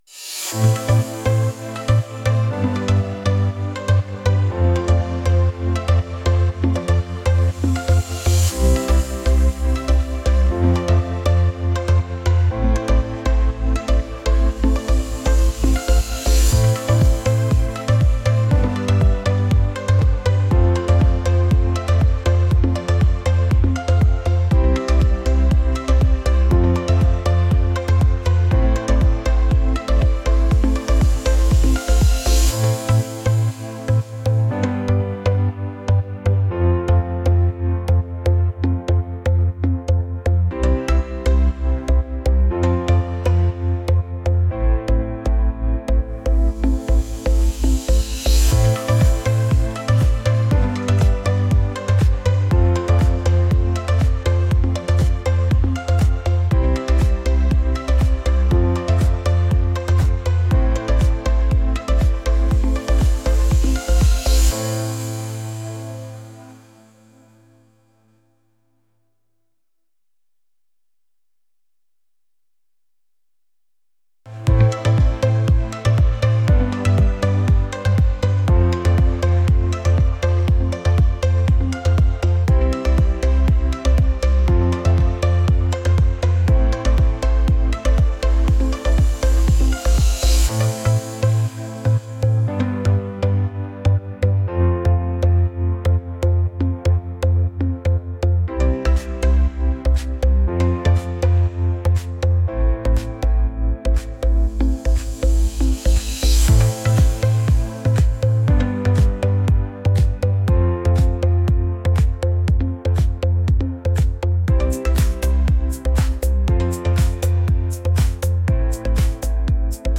pop | upbeat